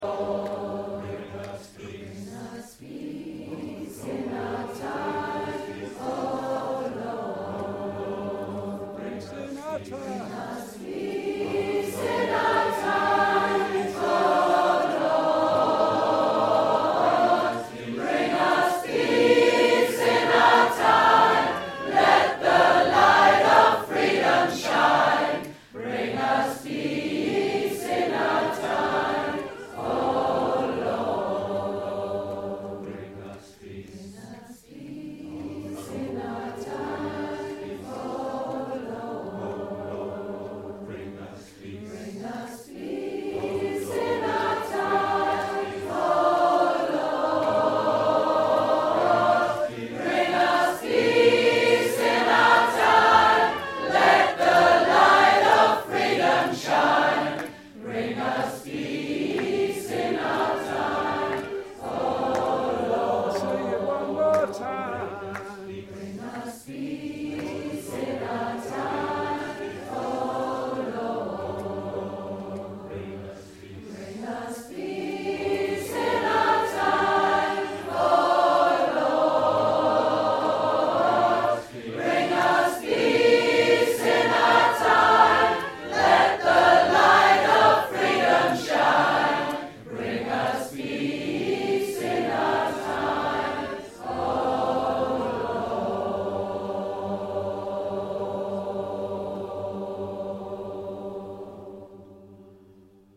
This group is for folks who have done some singing and would like to experience the exhilaration of singing in harmony (experienced singers also welcome!).
To hear samples of the kind of songs we sing - click the links below (mp3s courtesy Unicorn Voice Camp):
2]peace-in-our-time-(ensemble).mp3